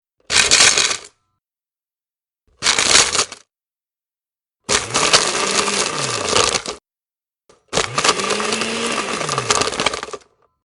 Free SFX sound effect: Ice Grind.
yt_02OfzIt2DEA_ice_grind.mp3